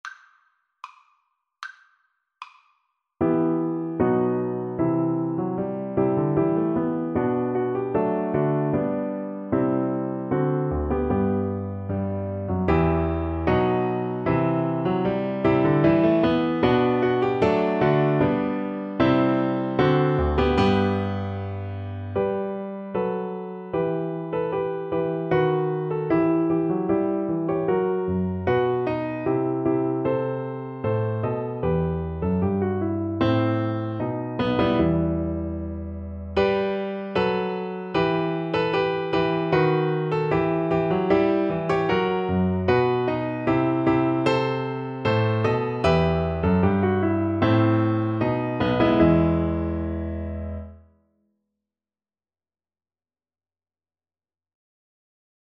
E minor (Sounding Pitch) (View more E minor Music for Viola )
Steadily =c.76
Classical (View more Classical Viola Music)